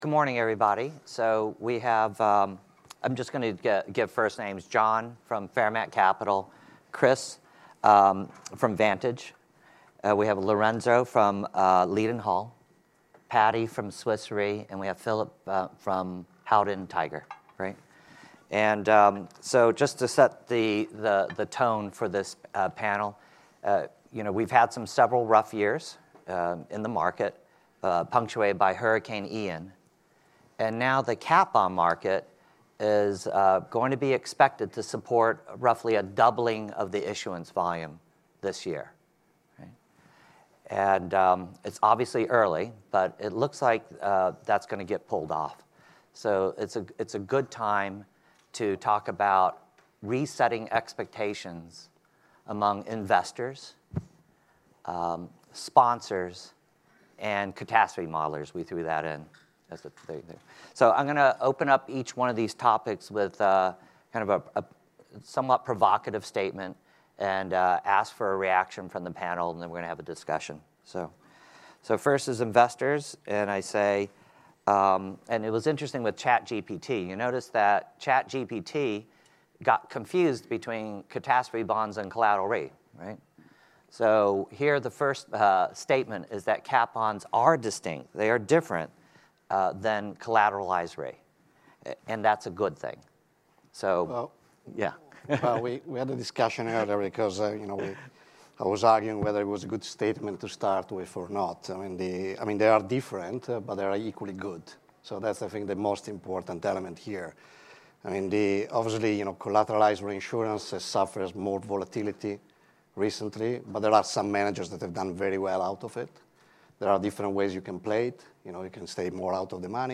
The first session from our ILS NYC 2023 insurance-linked securities (ILS) market conference from February 2023 features a catastrophe bond focused discussion featuring industry leaders and experts on the asset class.
This was the first session of the day at our Artemis ILS NYC 2023 conference, held in New York on February 10th 2023.
This first session from our ILS NYC 2023 conference features a panel discussion focused on the cat bond market, titled: (Re)setting expectations in catastrophe bonds.